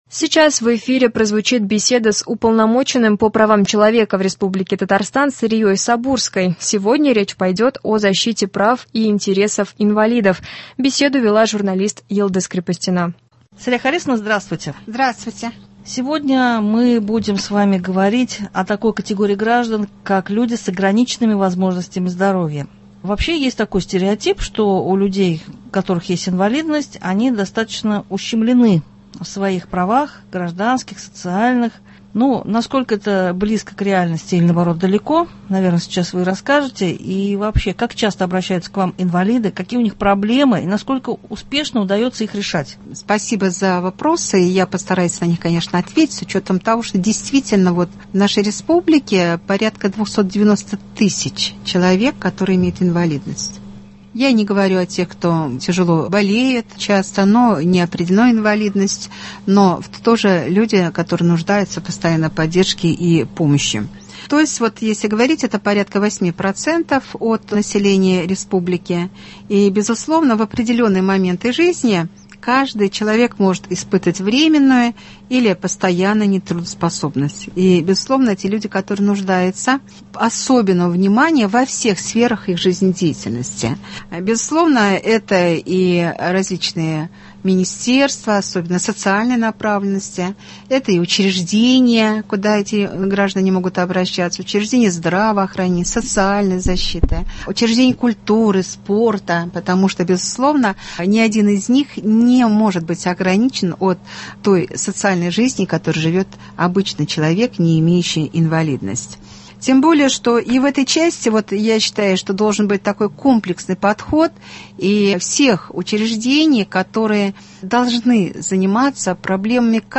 В эфире прозвучит беседа с Уполномоченным по правам человека в РТ Сарией Сабурской. Сегодня речь пойдет о защите прав и интересов инвалидов.